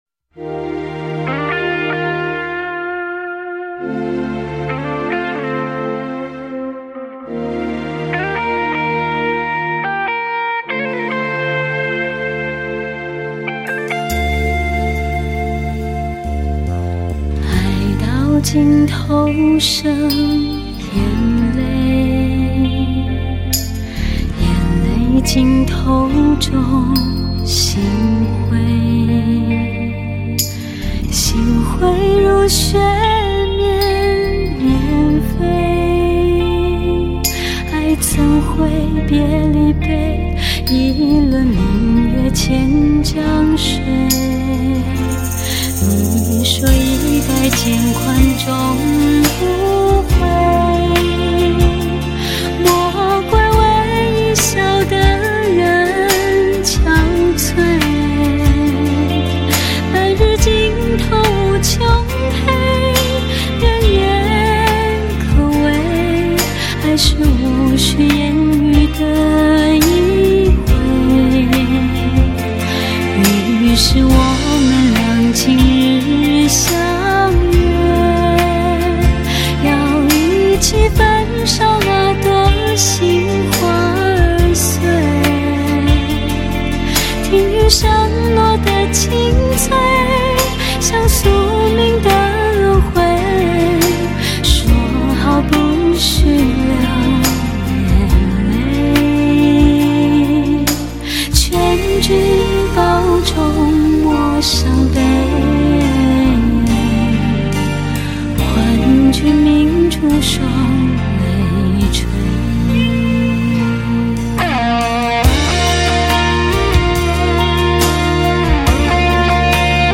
佛音
佛教音乐